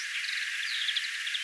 toinenkin trrr-tyyppinen ääni hempolta
Siis kaksi pientä tämän tyylistä ääntä
pitkässä laulusessiossa hempolta.
siina_on_hempolla_toinenkin_trrr-aani_kultahempon_aani_on_kuitenkin_puhdas_ja_selkea.mp3